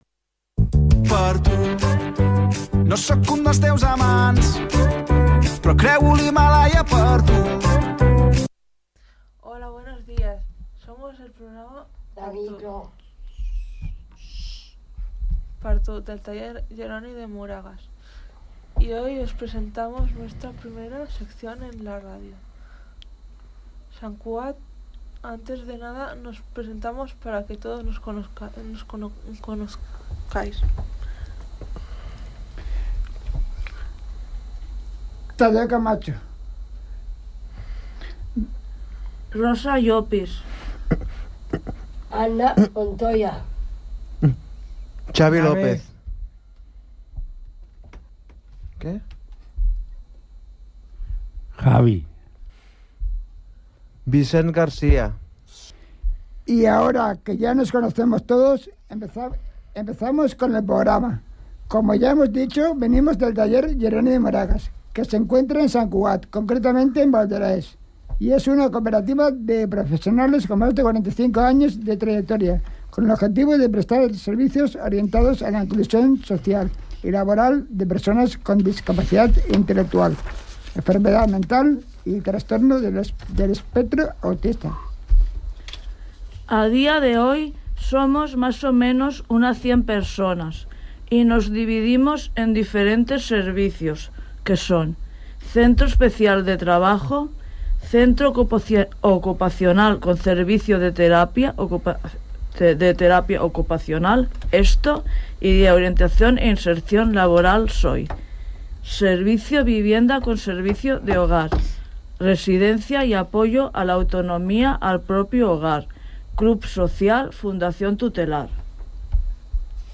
Locutors del taller/ Foto: Taller Jeroni de Moragas